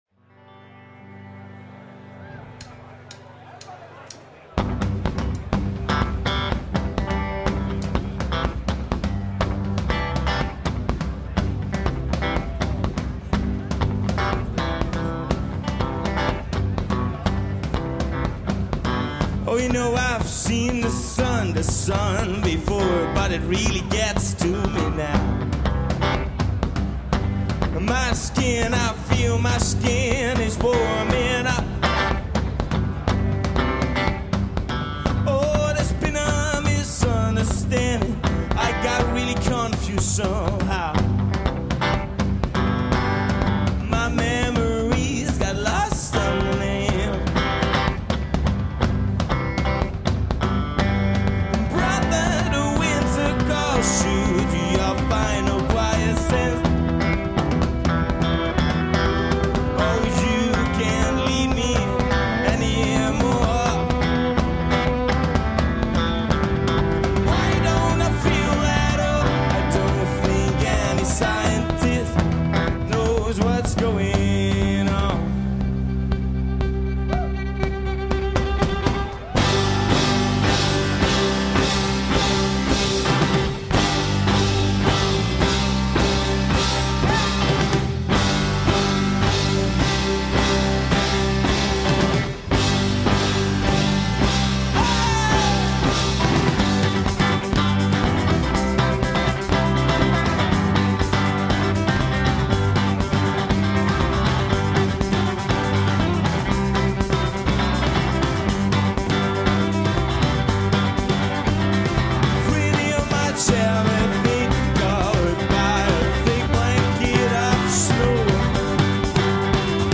in concert at Noorderslag Festival 2014
a Dutch/Finnish duo, aided by drummer
vocals/guitar
vocals/violin